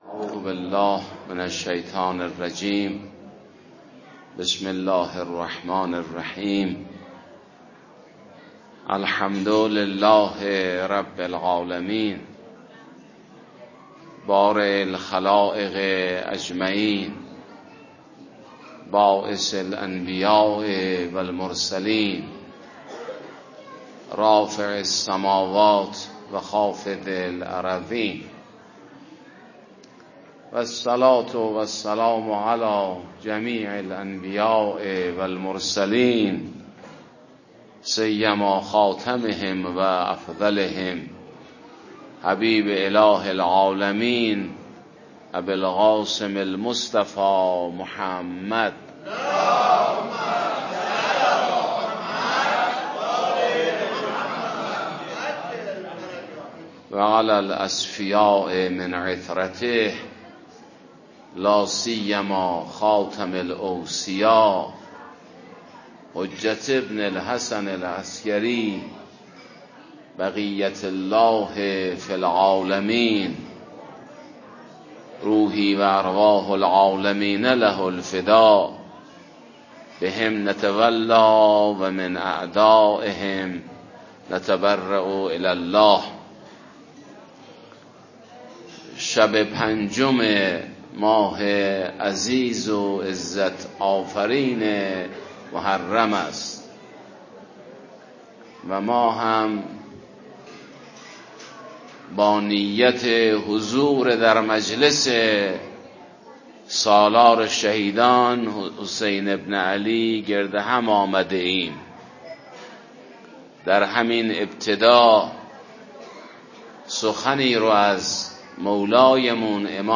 در شب پنجم محرم 1447 در جمع سوگواران و محبان اهلبیت عصمت و طهارت